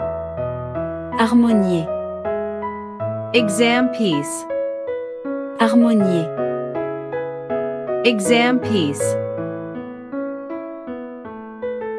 • 人声数拍
• 大师演奏范例
我们是钢琴练习教材专家